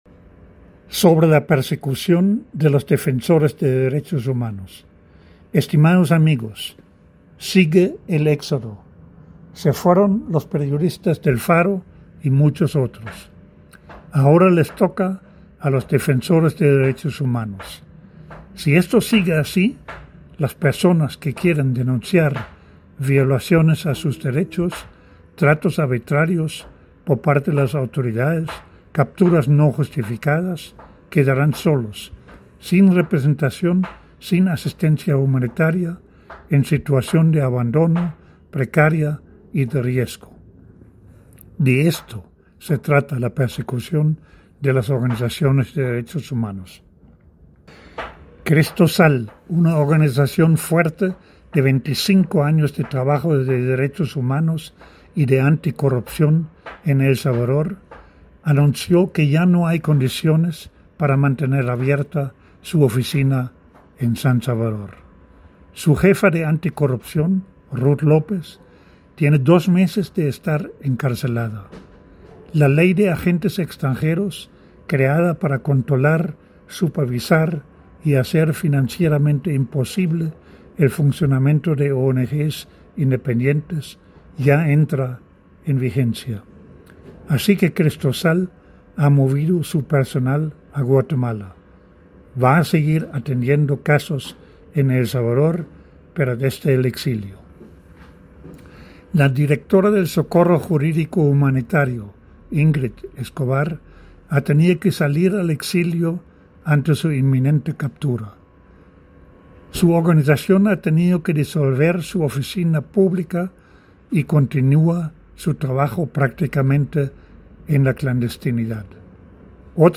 En la voz del autor: